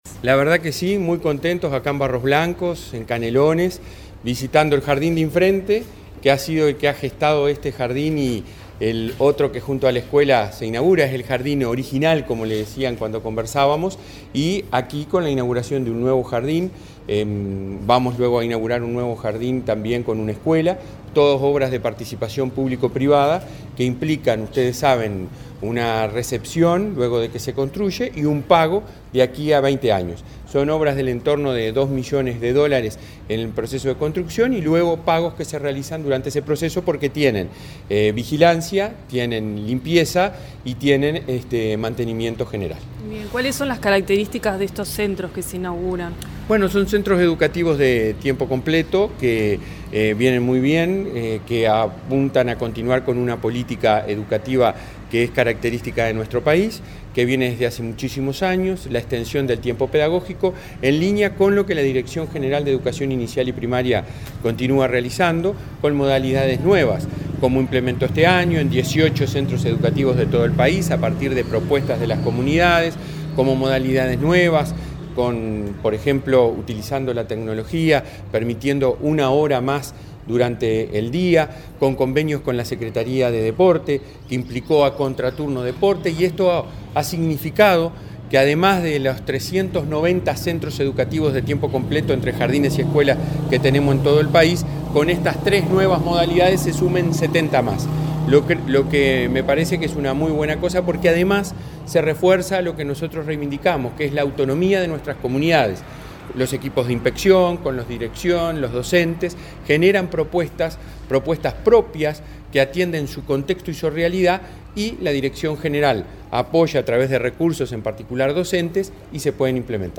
Entrevista al presidente de la ANEP, Robert Silva
Entrevista al presidente de la ANEP, Robert Silva 08/09/2023 Compartir Facebook X Copiar enlace WhatsApp LinkedIn Este viernes 8, el presidente de la Administración Nacional de Educación Pública (ANEP), Robert Silva, dialogó con Comunicación Presidencial en Barros Blancos, Canelones, donde participó en la inauguración de dos jardines de infantes y una escuela de tiempo completo.